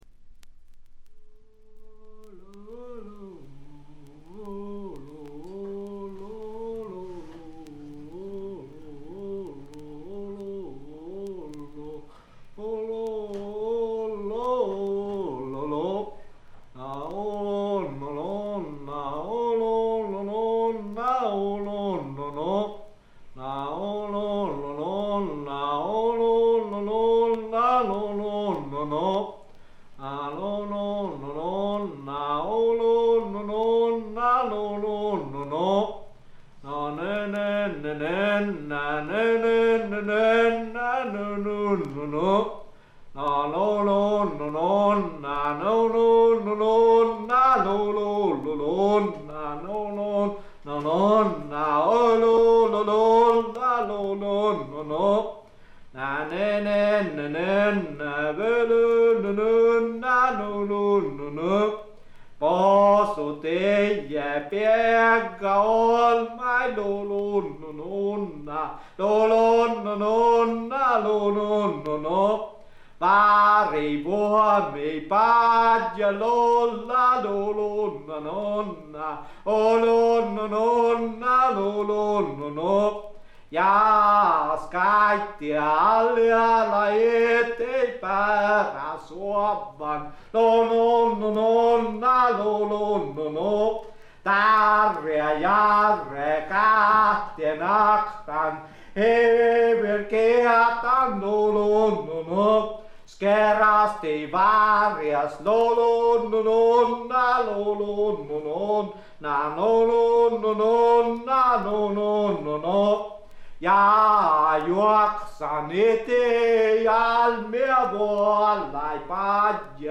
スウェーデンのトラッド・グループ
試聴曲は現品からの取り込み音源です。